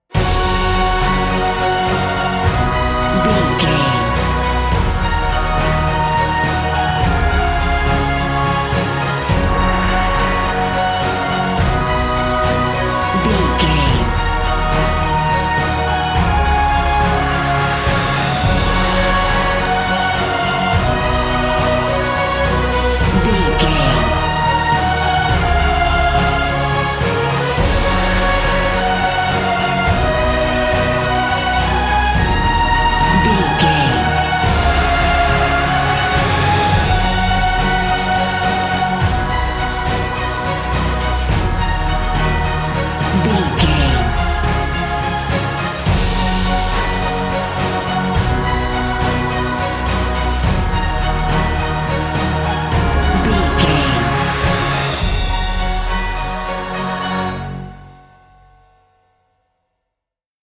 Ionian/Major
F♯
orchestral
uplifting
brass
cello
flutes
horns
percussion
strings
trumpet
violin